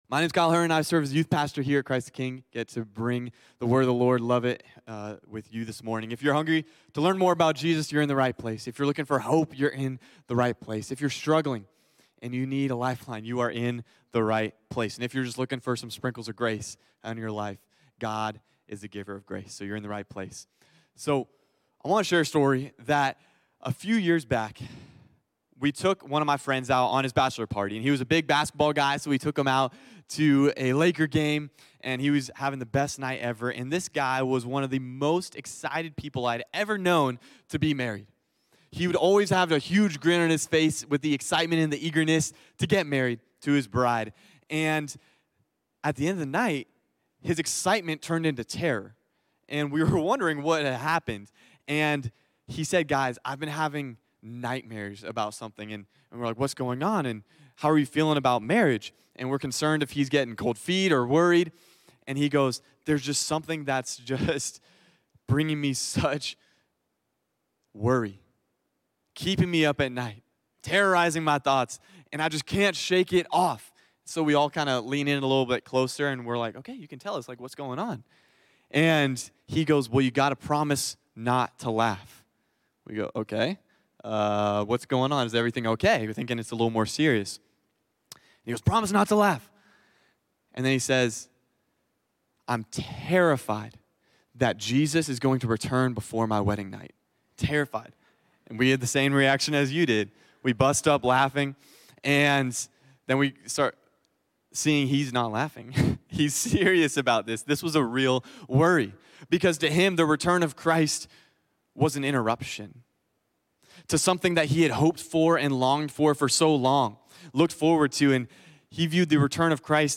Ctk-Sermon.mp3